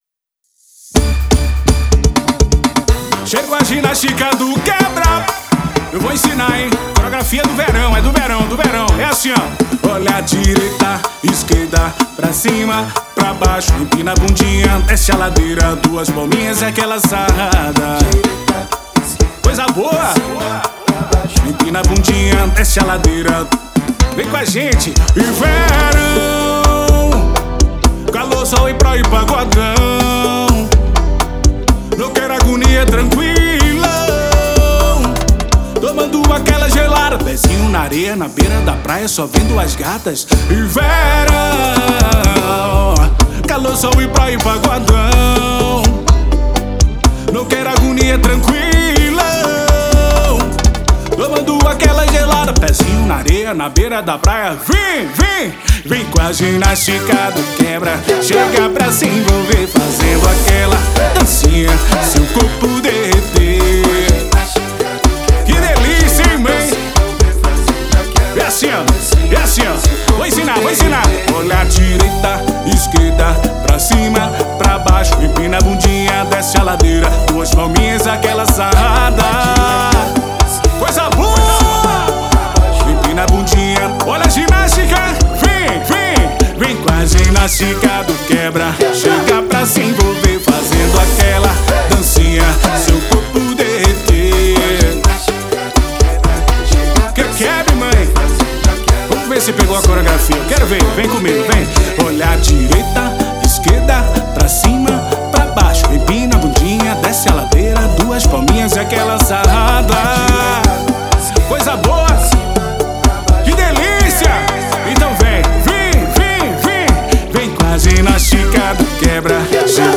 23 anos de bagunça, alegria e pagodão!
axé